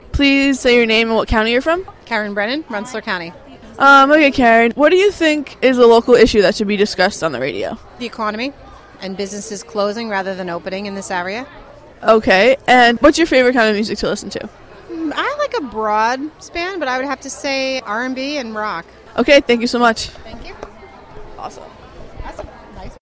Interview
Greene County Youth Fair: Jul 22, 2010 - Jul 25, 2010